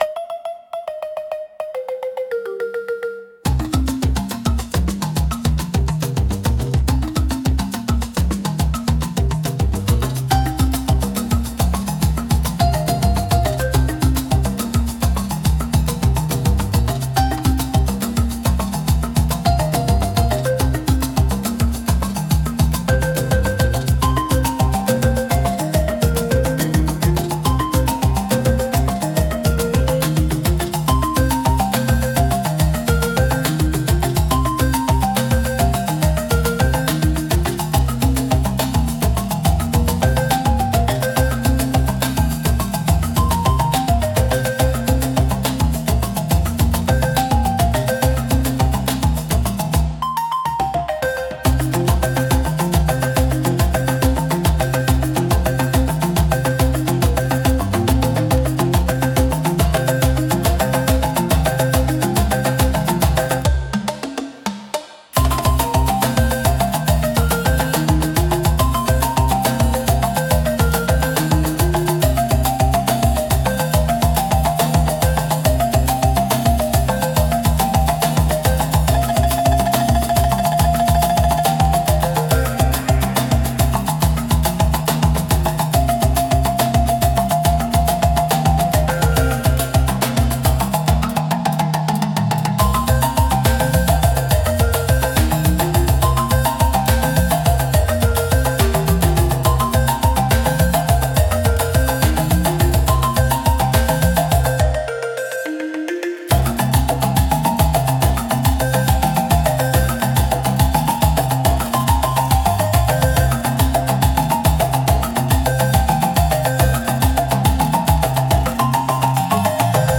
聴く人に深い興味や未知への探求心を喚起し、エキゾチックな異世界感を演出します。迫力と神秘性が共存するジャンルです。